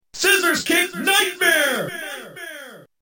The announcer will also say the name of the move depending on the region: